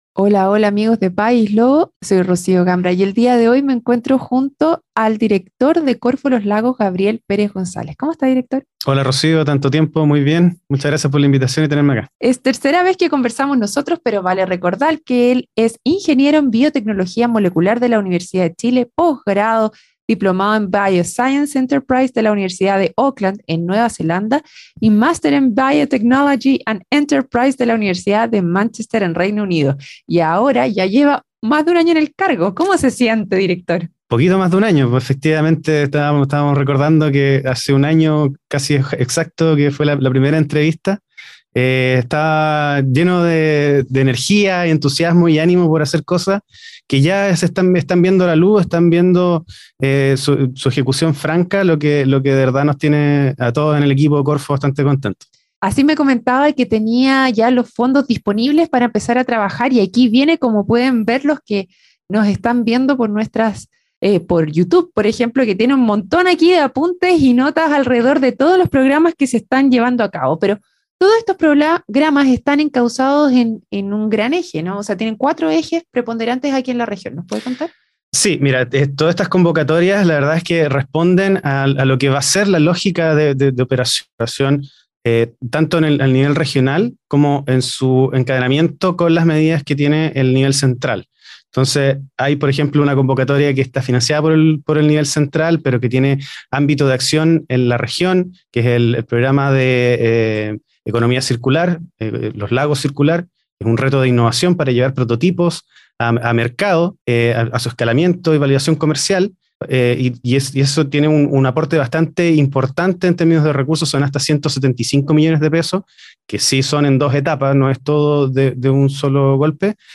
En su emisión más reciente, conversó con Gabriel Pérez González, director regional de Corfo Los Lagos.